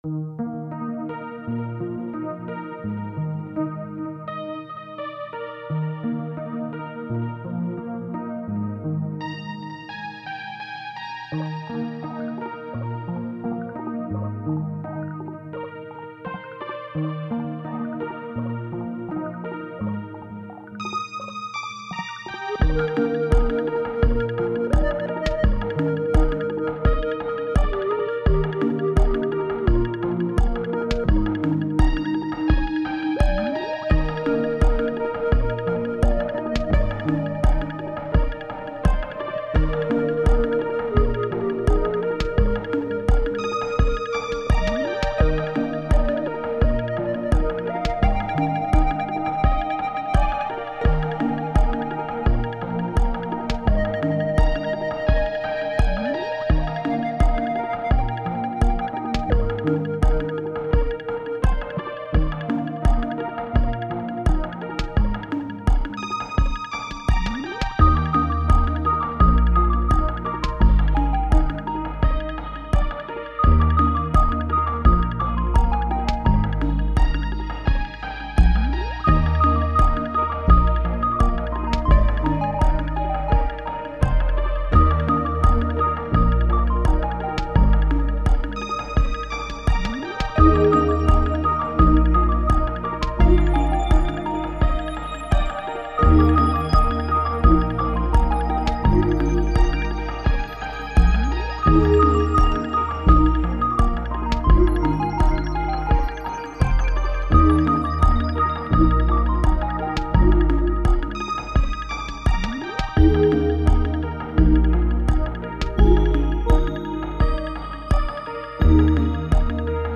なんとなく一曲出来ました。
曲かどうかも怪しいです、単なる音の羅列かも・・・。